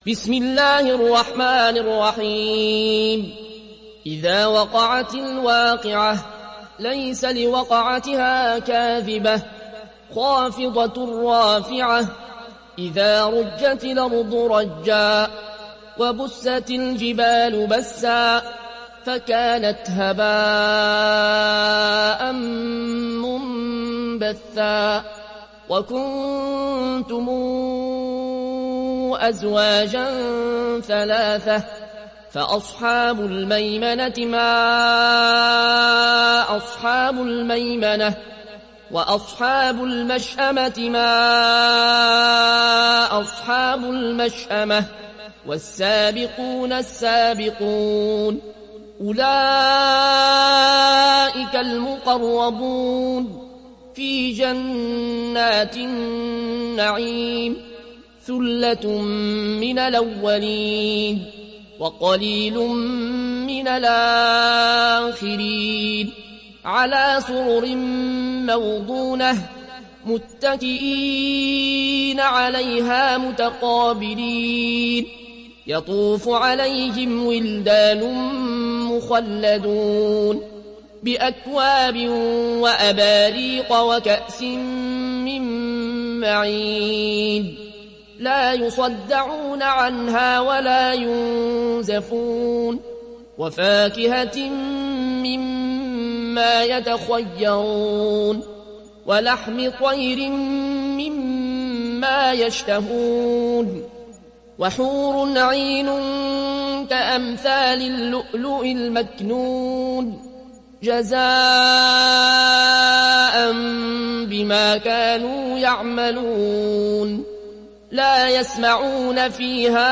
تحميل سورة الواقعة mp3 بصوت العيون الكوشي برواية ورش عن نافع, تحميل استماع القرآن الكريم على الجوال mp3 كاملا بروابط مباشرة وسريعة